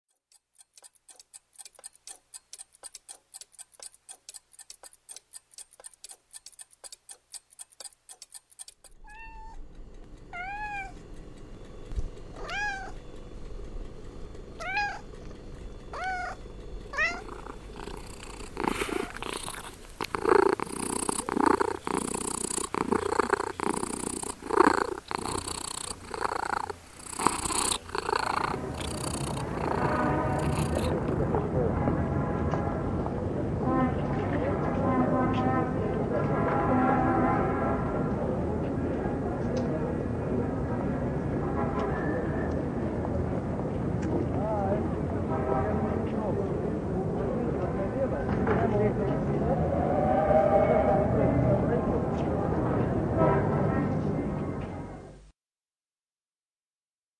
Zvuk-koshka-u-okna.mp3